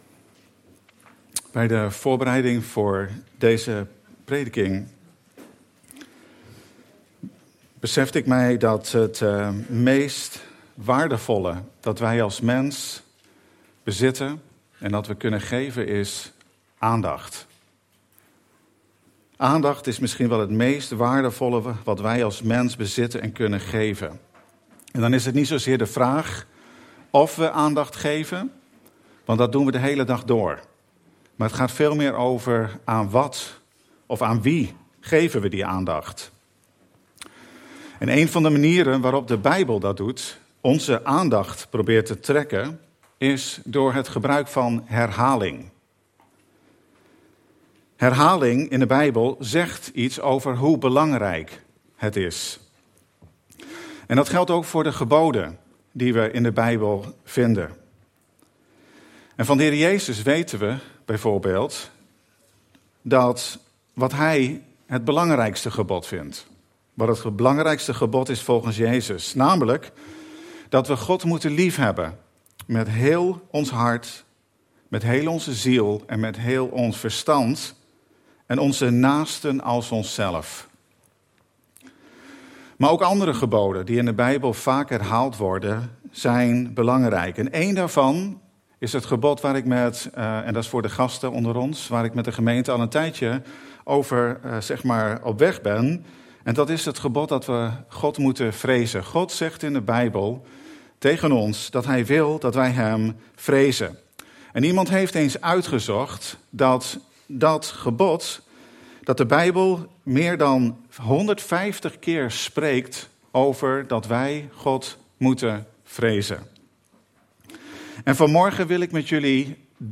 De vreze des HEEREN Passage: Psalm 130:4 Dienstsoort: Eredienst « Pasen